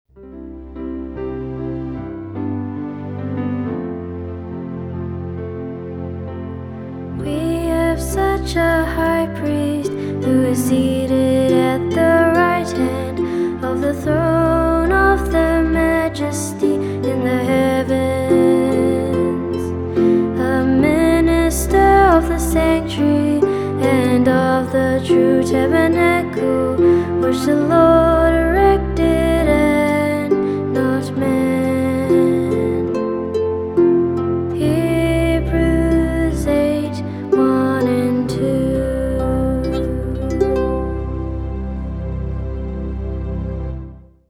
Vocalist
Keyboard
Harp